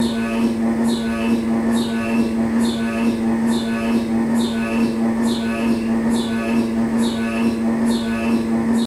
MRI Pump Mono Loop